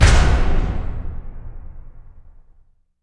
LC IMP SLAM 1B.WAV